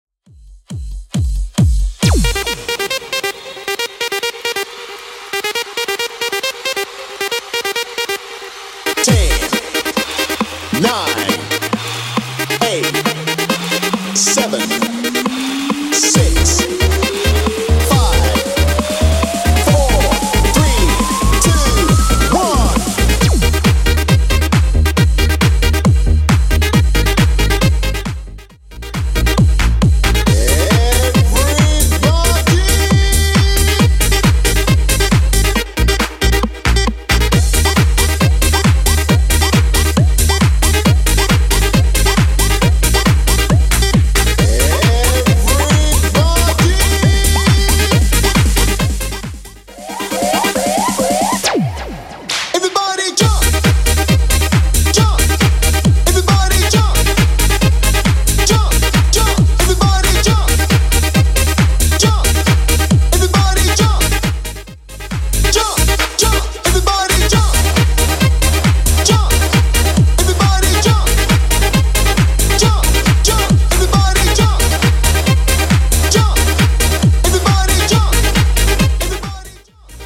Genre: 90's
BPM: 107